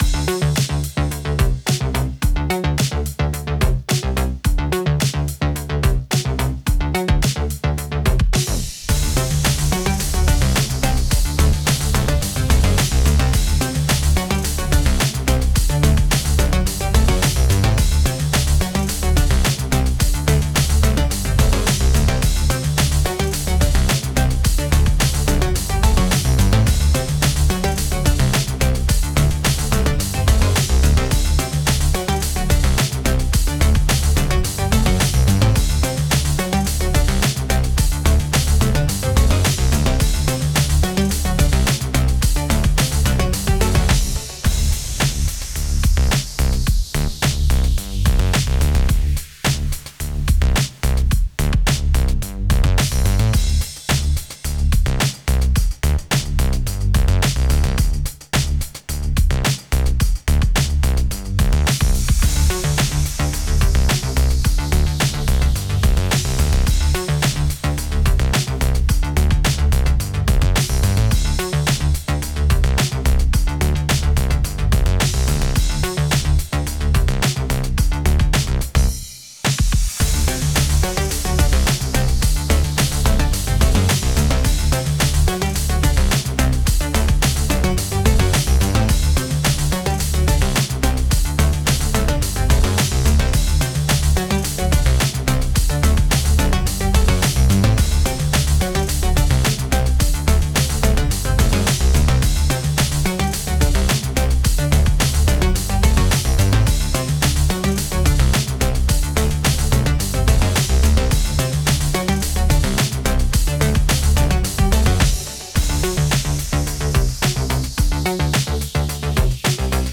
ちょっと不思議で明るい雰囲気のゲーム風BGMです
▼ループ版